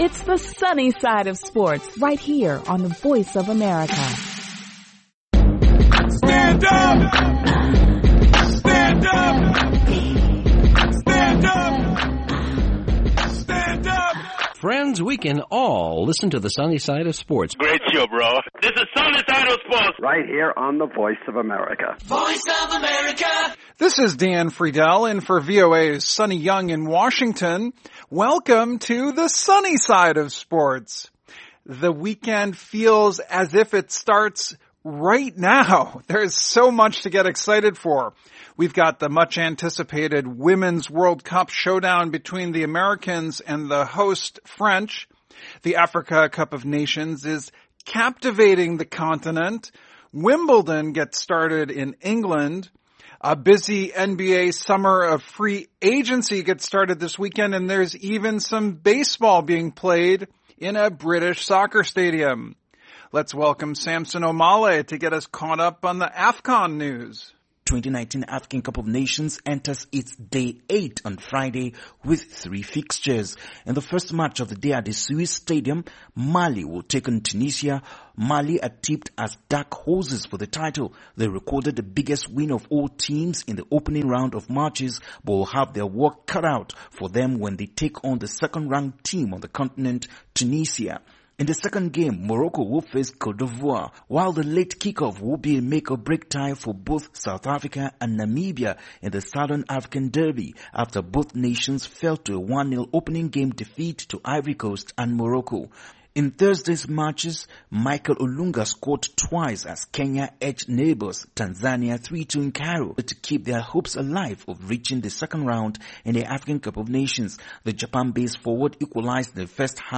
a lively presentation that combines humor, props, sound effects and correspondent reports from Africa and all over the...